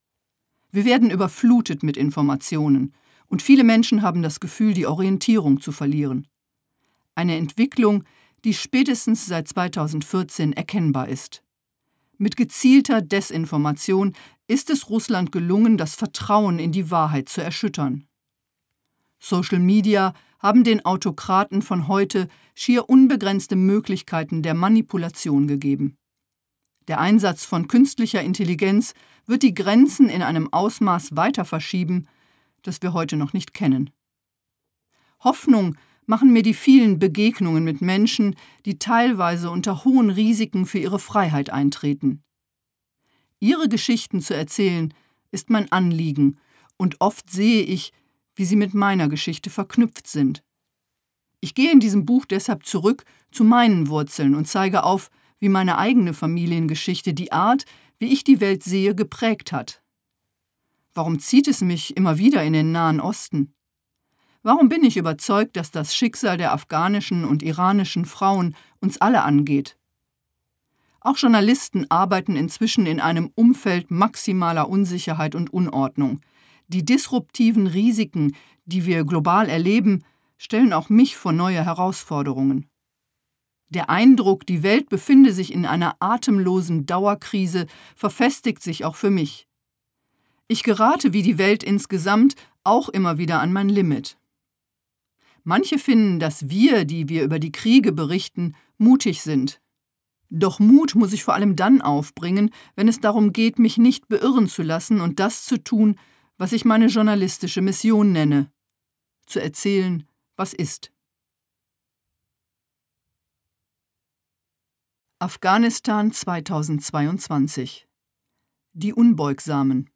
Gekürzt Autorisierte, d.h. von Autor:innen und / oder Verlagen freigegebene, bearbeitete Fassung.
Erzählen, was ist Gelesen von: Katrin Eigendorf
• Sprecher:innen: Katrin Eigendorf